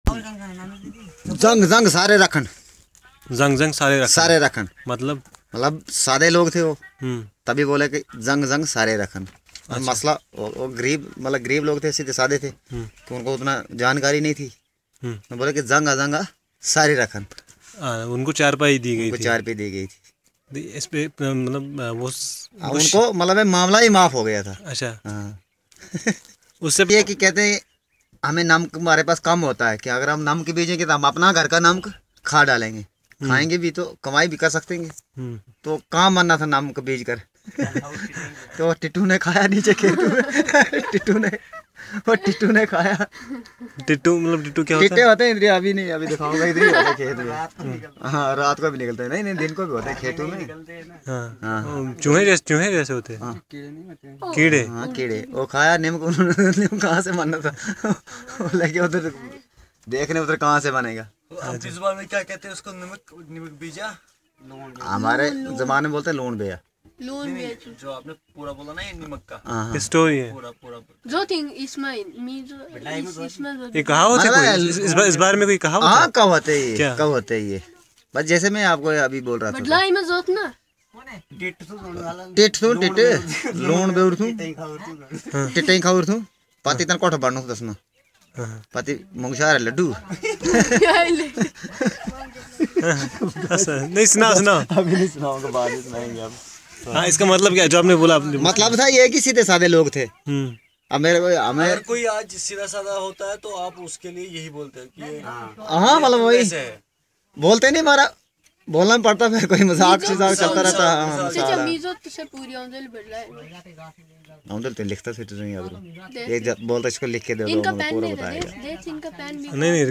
Conversation about oral literature and idioms - Part 1